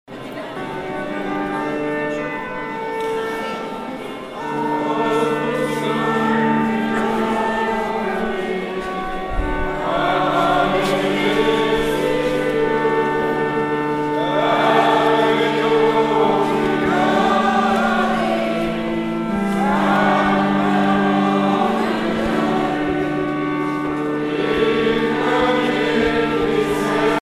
enfantine : prière, cantique
Genre strophique
Pièce musicale éditée